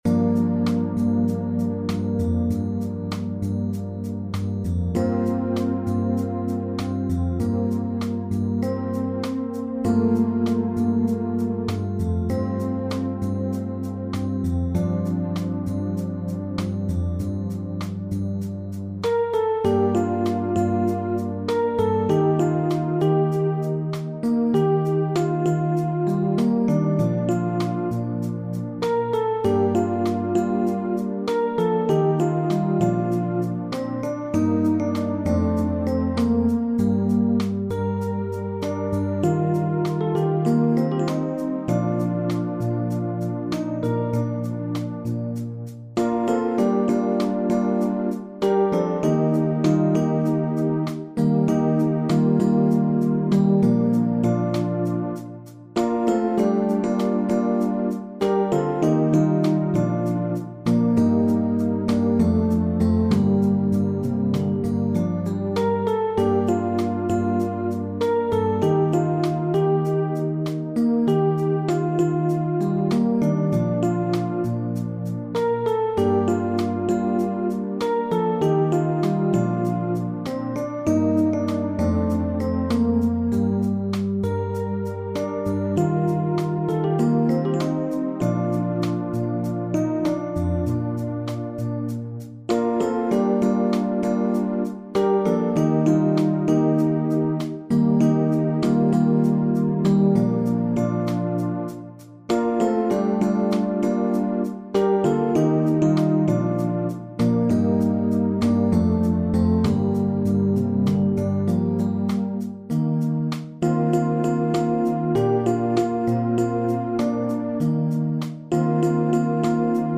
SATB | SSAB | TTBB